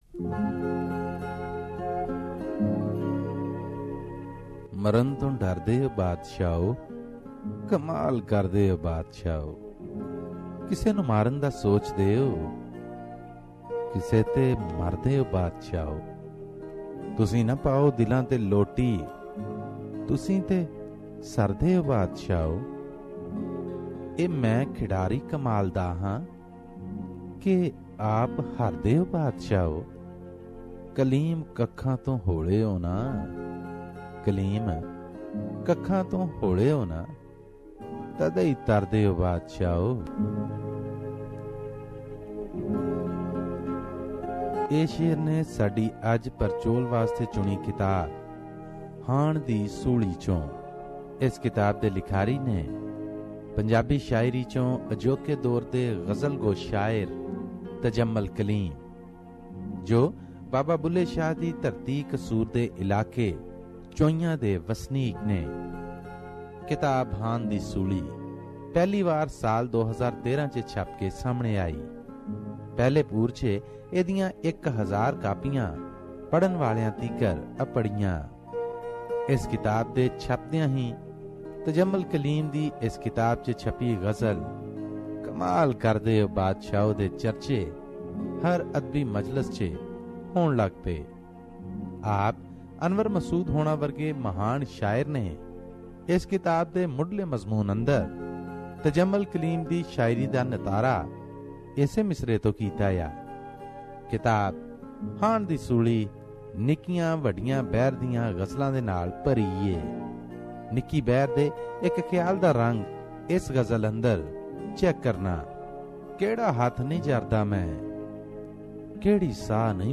Here is a book review from the writer who is from land of Baba Bulle Shah i.e. Kasur in Pakistan